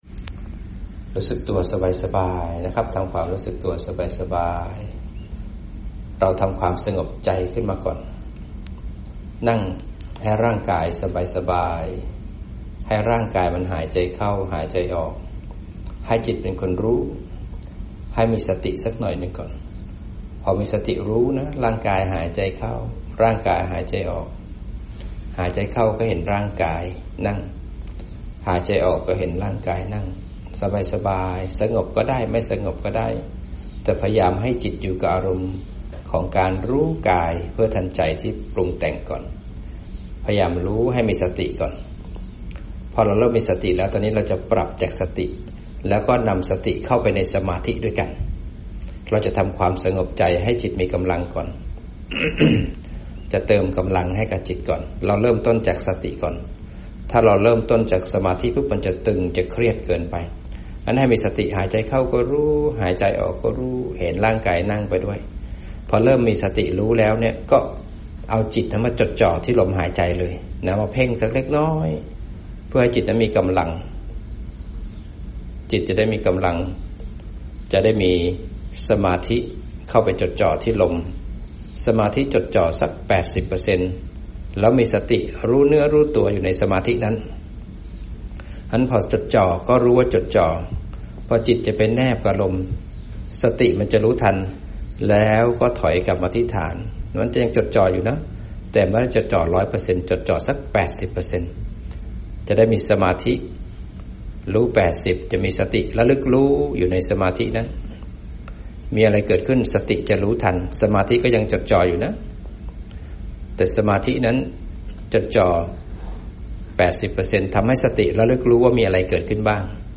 อจ นำปฏิบัติฝึกจิตให้สงบ สติ และปัญญา